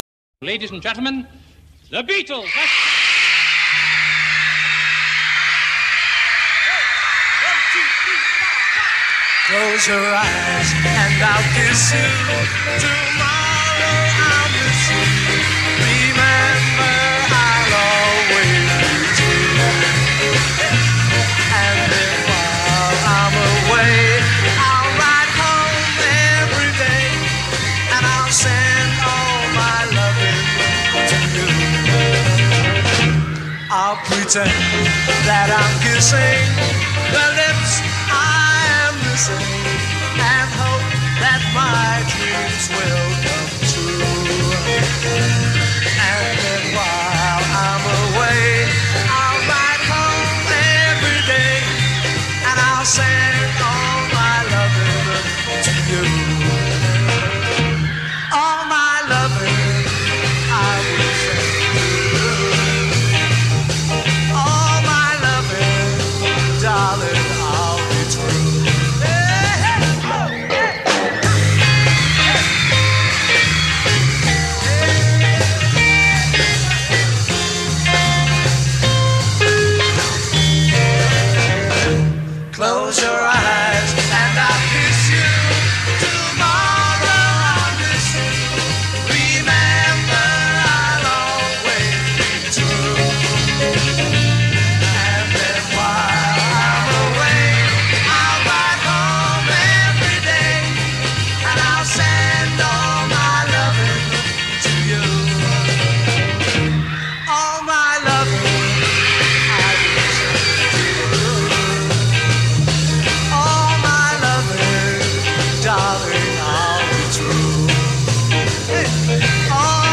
B Chorus 1 : 8 solo voice over vocal harmonies c
B Chorus 2 : 8 guitar repeat of closing chorus material